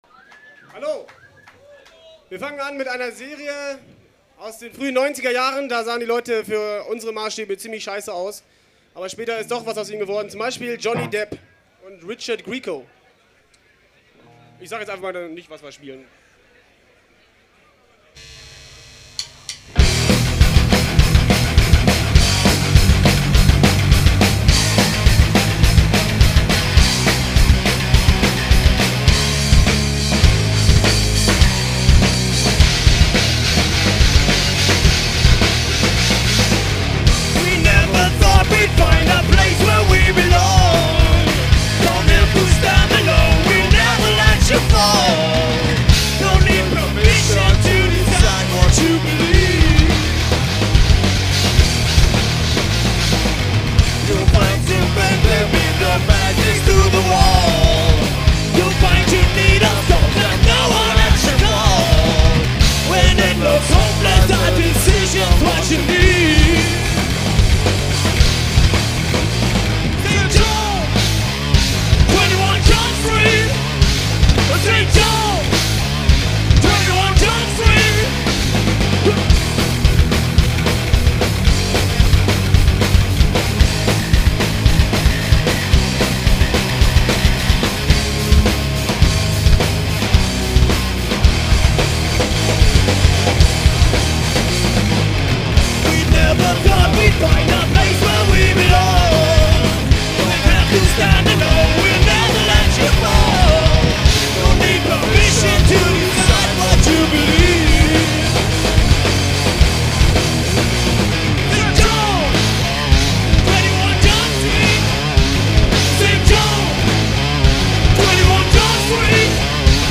Cover-Songs (live):